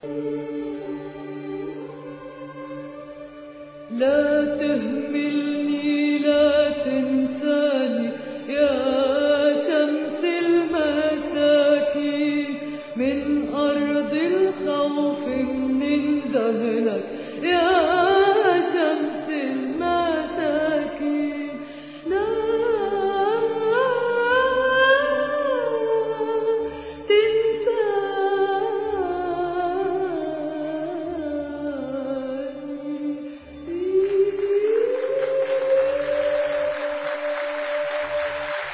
prayer